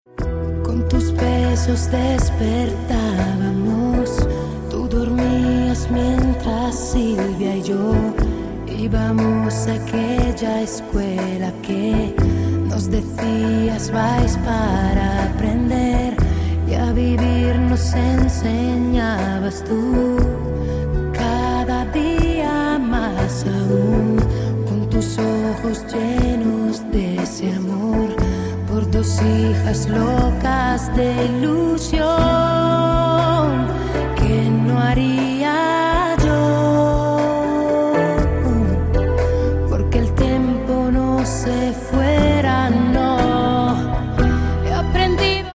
поп
женский вокал
душевные
спокойные
лирические